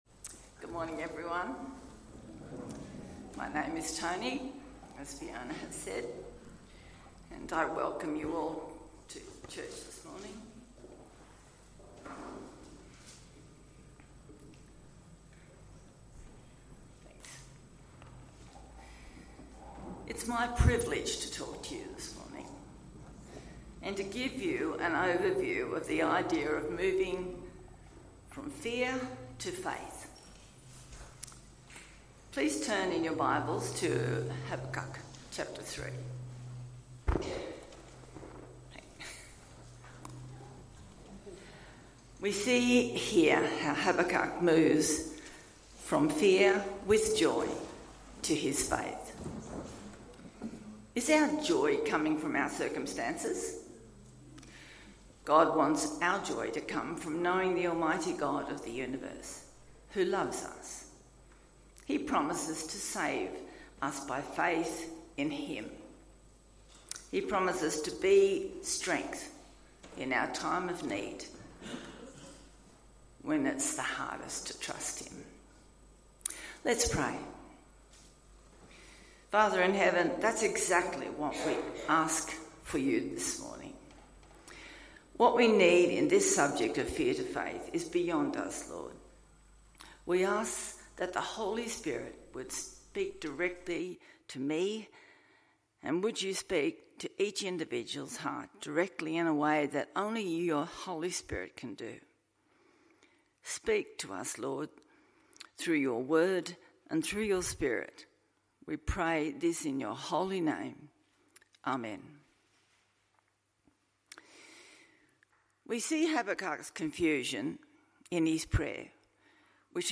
Shaking your fist at God Passage: Habakkuk 3:1-19, Psalm 103 Service Type: AM Service « Dear God